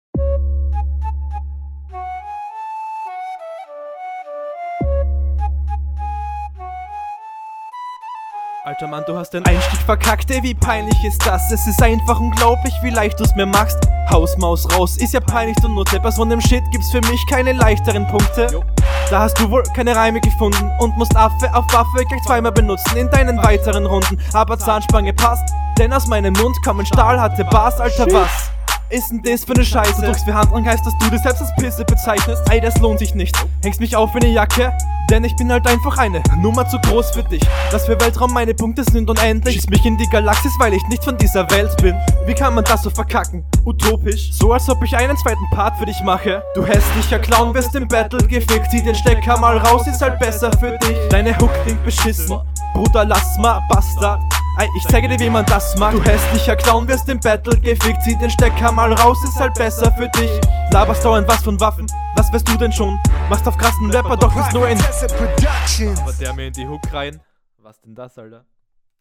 Du hast schön gekonternt und du kommst gut auf den beat, bist gut verständlich und …
Mische und Stimmeinsatz direkt schwächer, dein Stimme klingt mehr nach Reden als wirklich rappen, aber …